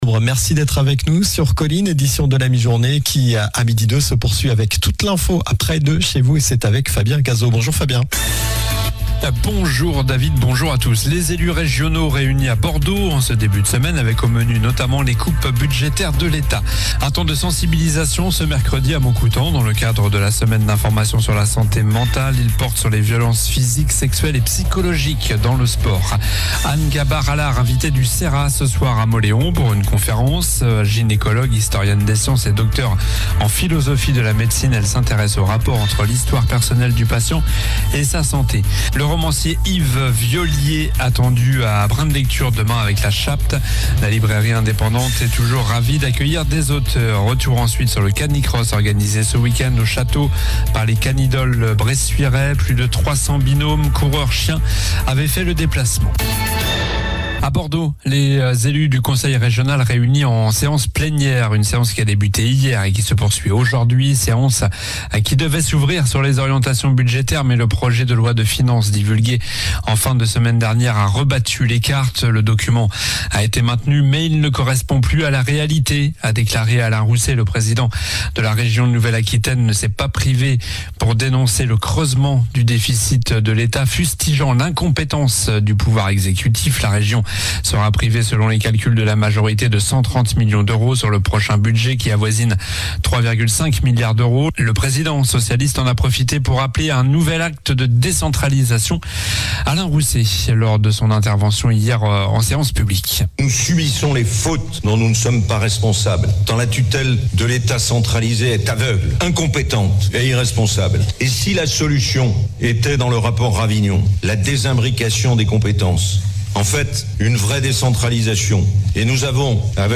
Journal du mardi 15 octobre (midi)